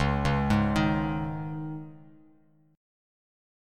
C#sus2 chord